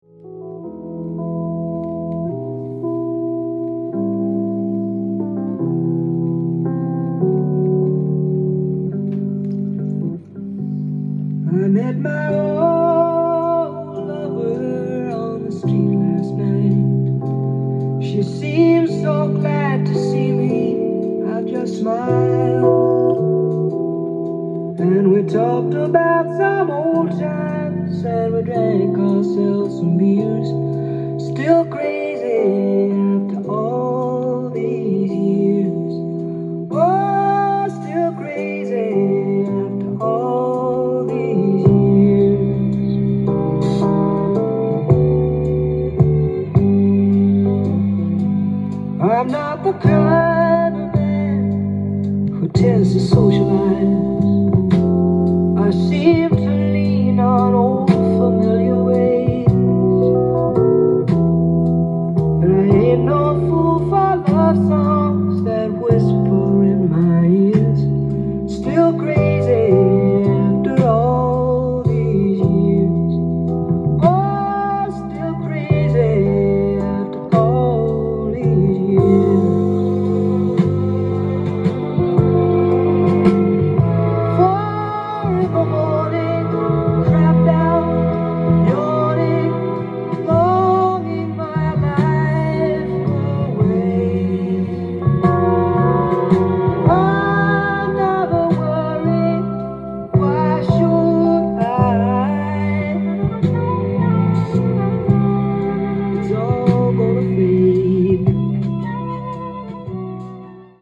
LP
店頭で録音した音源の為、多少の外部音や音質の悪さはございますが、サンプルとしてご視聴ください。
エレピも最高に気持ちいいメロウ／バラード